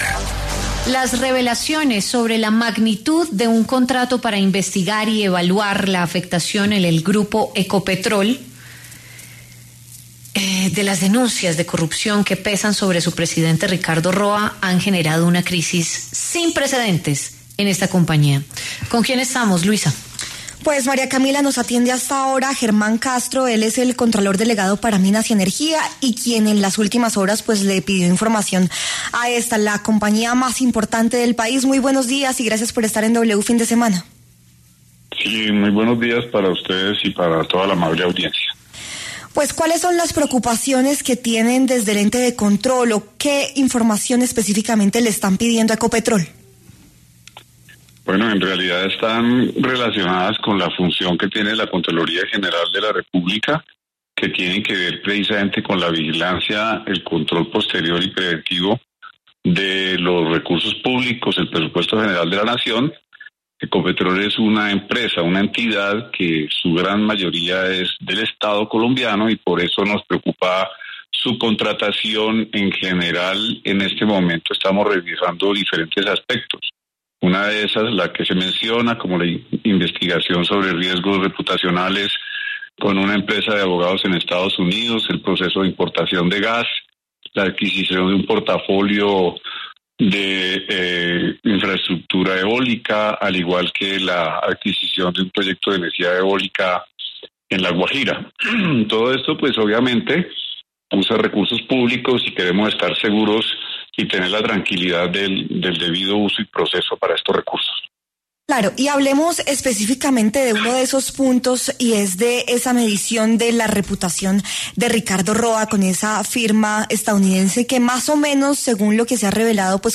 Germán Castro, controlador delegado para Minas y Energía, explicó en La W las inevstigaciones que se llevan a cabo en la petrolera sobre las recientes revelaciones de un contrato para evaluar a la estatal.